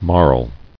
[marl]